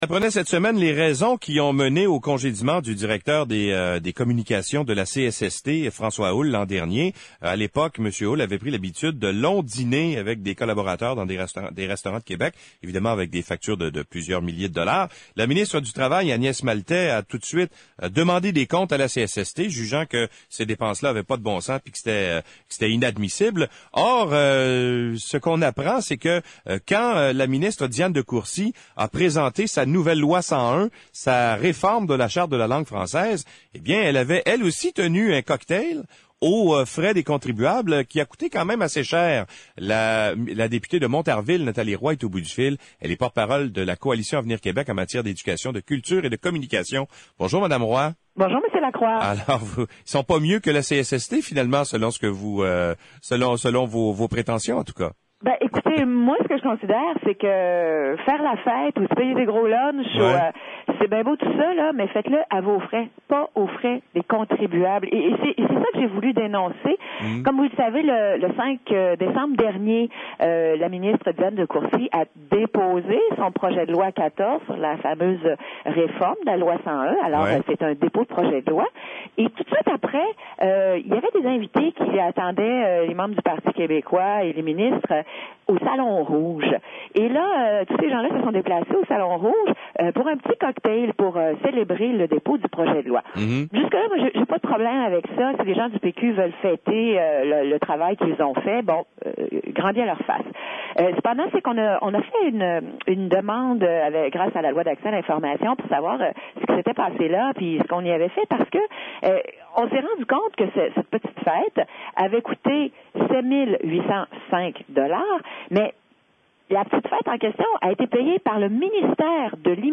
La députée Nathalie Roy en entrevue à l’émission «Paroles de politiciens»